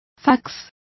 Complete with pronunciation of the translation of faxes.